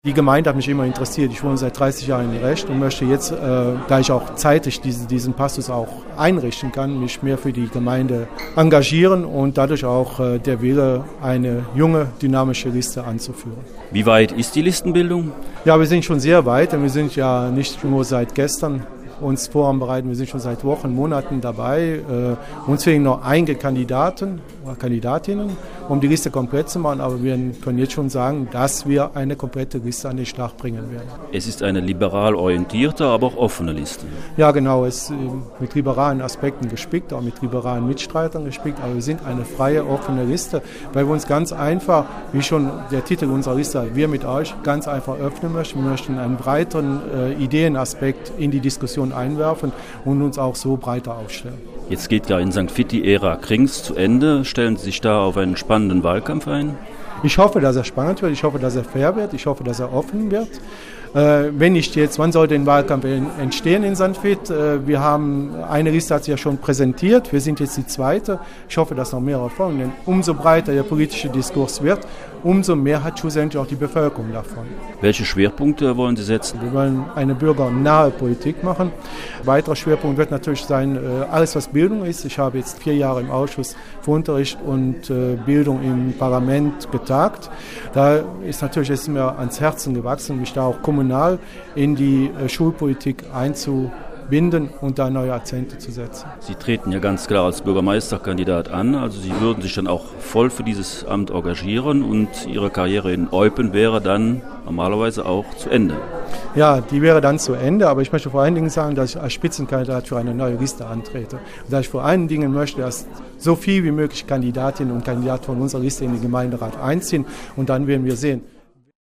von dem liberalen Politiker wissen: